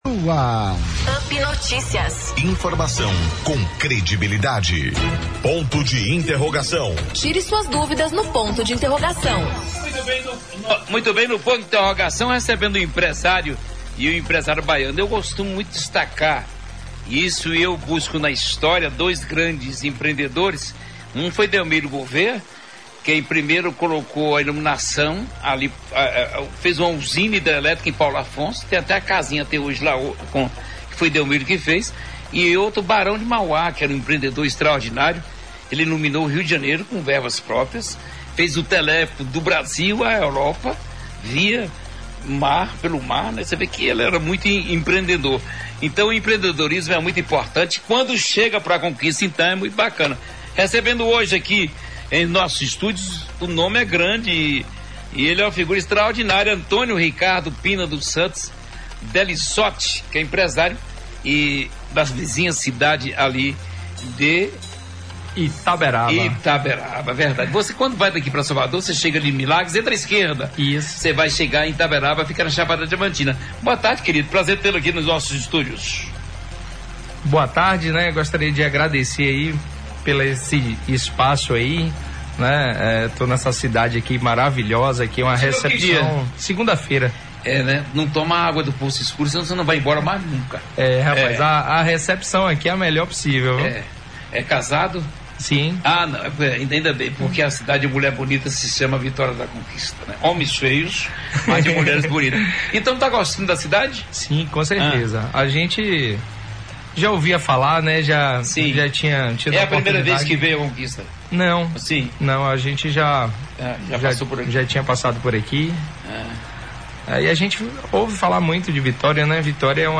Entrevista ao UP Notícias